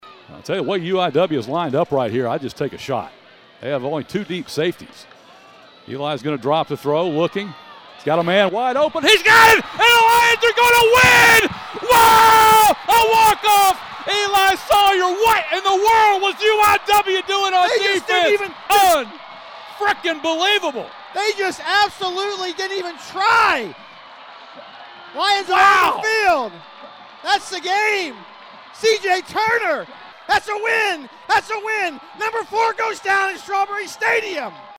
RADIO - Game-Winning Call vs. UIW
final_call.mp3